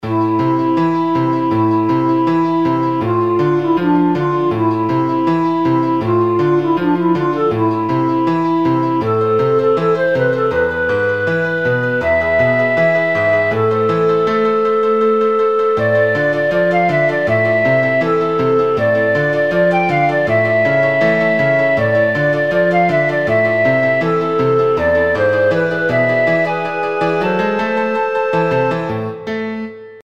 מנגינה
המנגינה נוגנה בתוכנת מידי שקוראת תוים.
מרגיע מאוד!